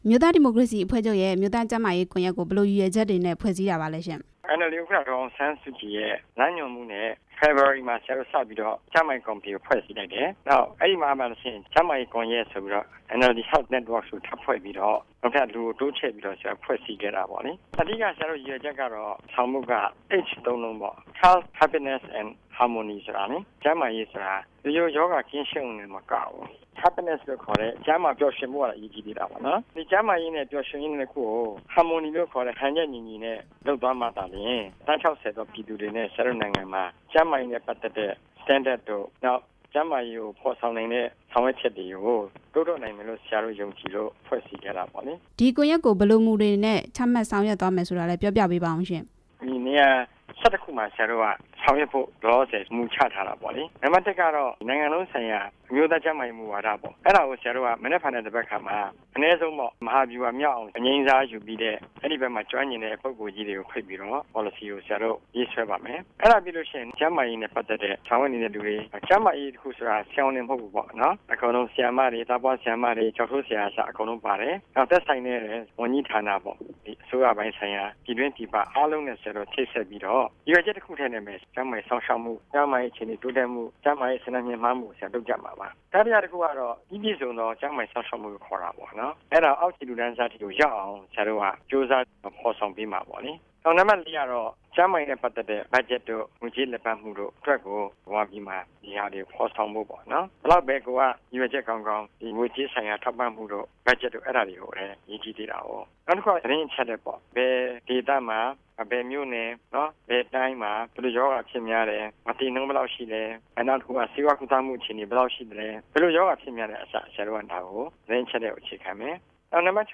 ရန်ကုန်မြို့ Park Royal ဟိုတယ်မှာ ဒီကနေ့ မနက်ပိုင်းကကျင်းပတဲ့ NLD ပါတီရဲ့ အမျိုးသားကျန်းမာရေးကွန်ရက် ဖွင့်ပွဲအခမ်းအနားမှာ အမျိုးသားဒီမိုကရေစီအဖွဲ့ချုပ် ဥက္ကဌ ဒေါ်အောင်ဆန်း စုကြည်က မြန်မာပြည်သူတွေအတွက် ကျန်းမာရေးစောင့်ရှောက်မှုစနစ် တိုးတက်ကောင်းမွန်လာဖို့ အမြန်ဆုံး စတင်ဆောင်ရွက်ဖို့ လိုအပ်နေပြီဖြစ်ကြောင်း၊ အနာဂတ်မျိုးဆက်တစ်ခုအထိ အချိန်မဆိုင်းတော့ပဲ အခုအချိန်ကစပြီး ဆောင်ရွက်ရမယ် လို့ ဒေါ်အောင်ဆန်းစုကြည်က ပြောခဲ့ပါတယ်။